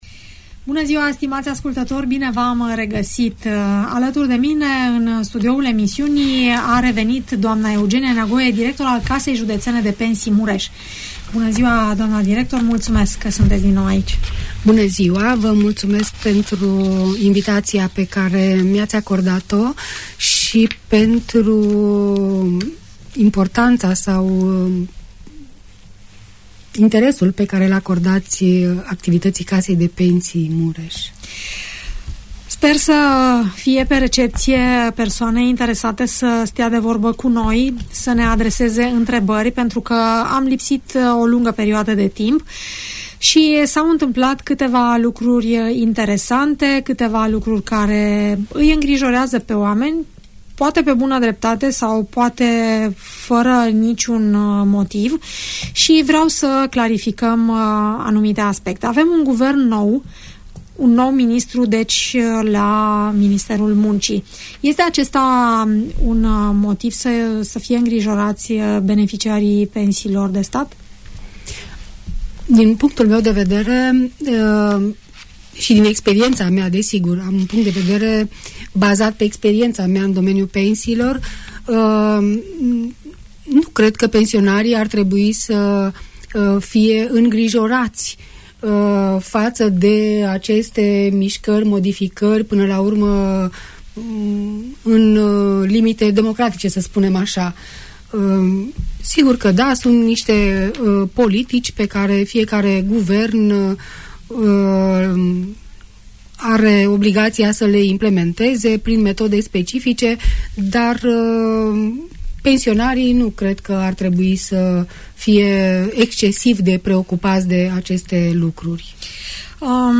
Audiență radio la Casa Județeană de Pensii Mureș - Radio Romania Targu Mures